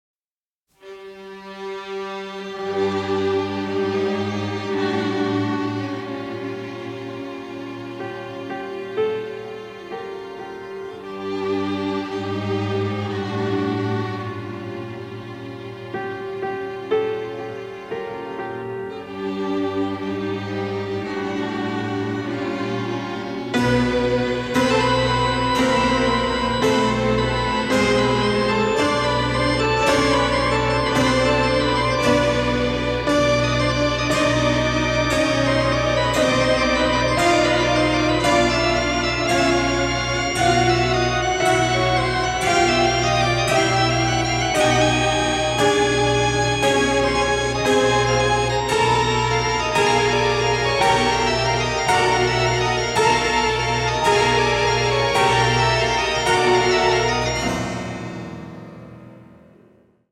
a wonderful romantic horror score
in beautiful pristine stereo